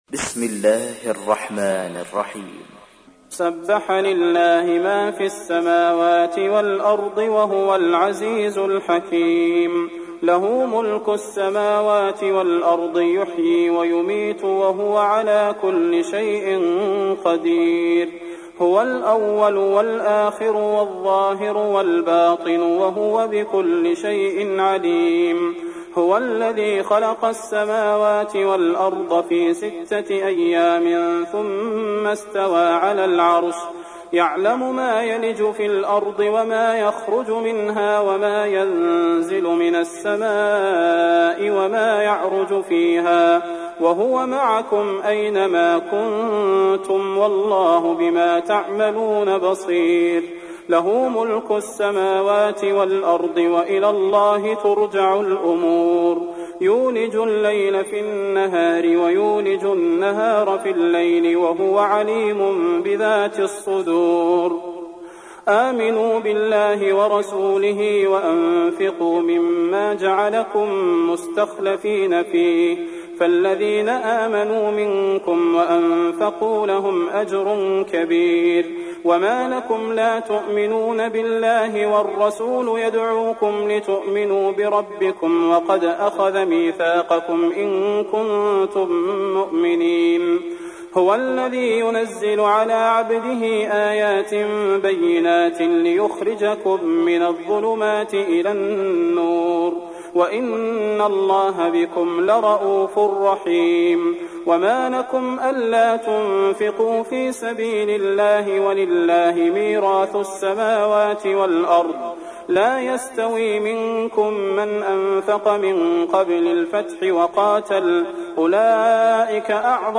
تحميل : 57. سورة الحديد / القارئ صلاح البدير / القرآن الكريم / موقع يا حسين